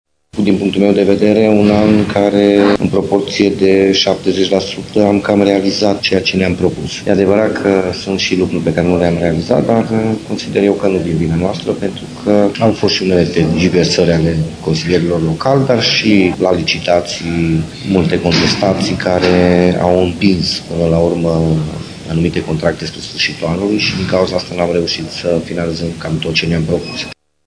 Actualul viceprimar Claudiu Maior a declarat pentru Radio Tîrgu-Mureş că în anul care a trecut aproximativ 70% din obiective au fost realizate: